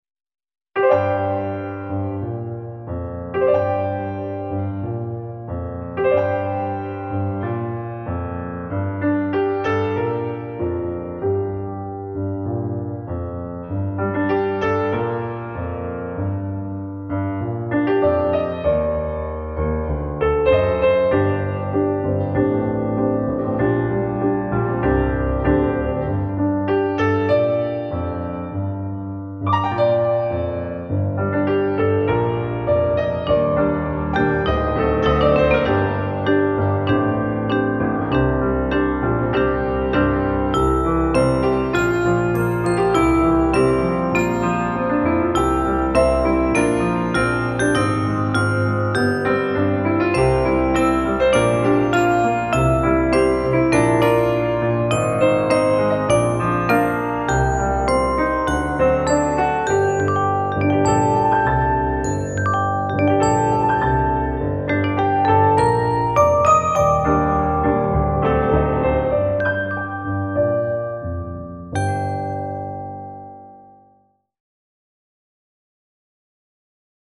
Largo [0-10] - - amour - romantique - jazzy - doux - nuit